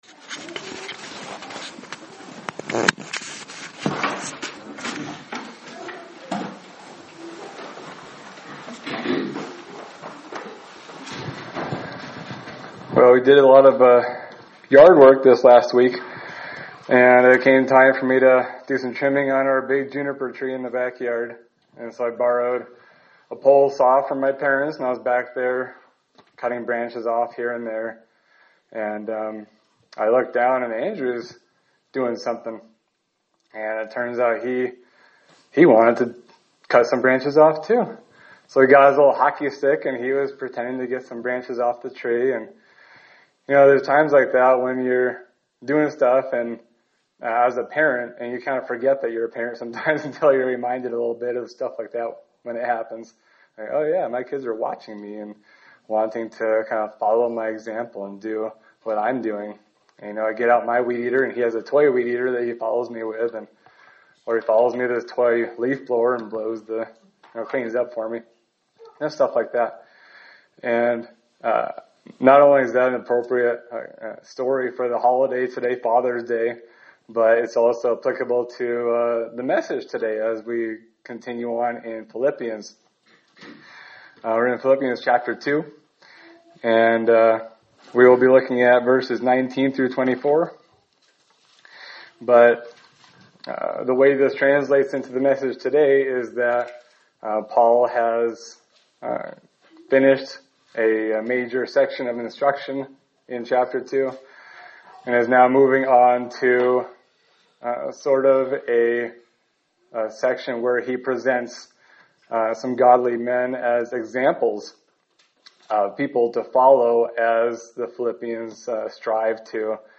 Sermon for June 15, 2025
Service Type: Sunday Service